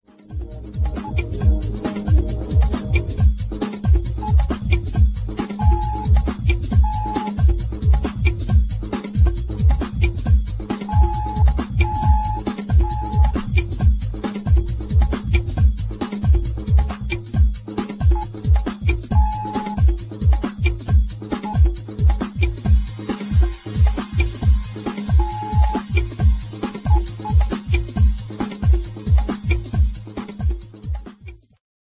Bouncy analogue basslines